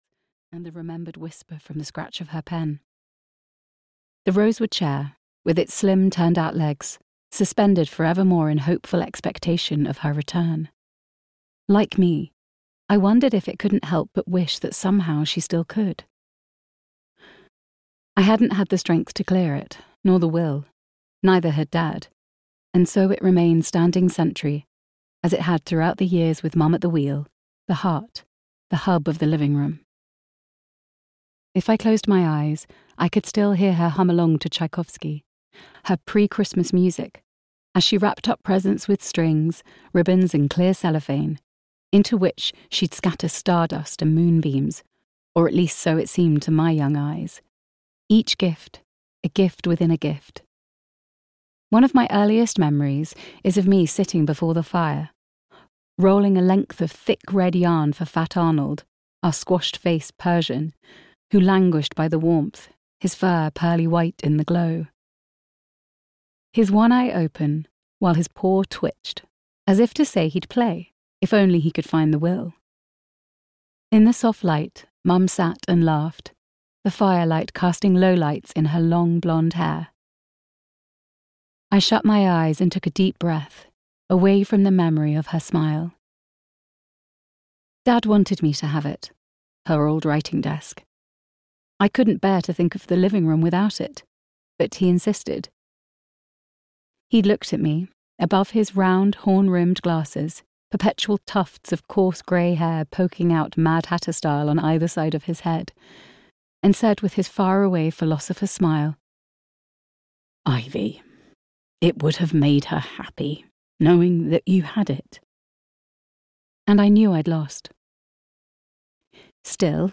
A Cornish Christmas (EN) audiokniha
Ukázka z knihy